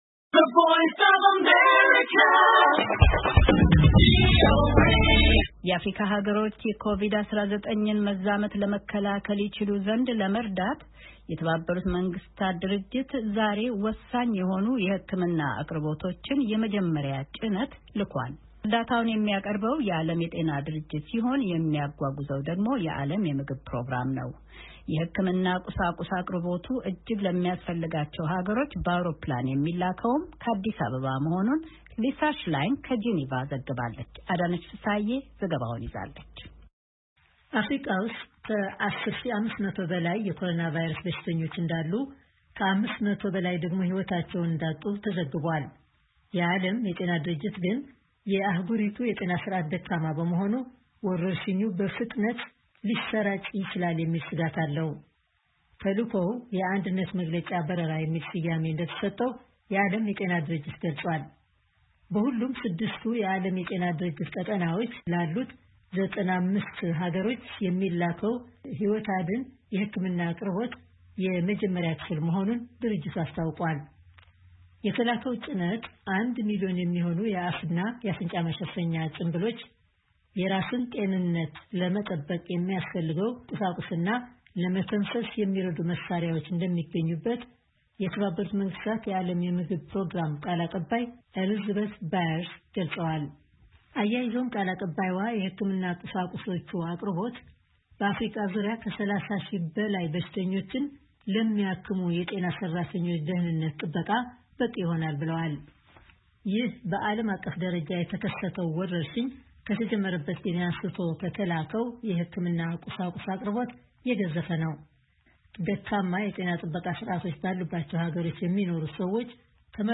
ከጄኔቫ ዘግባለች።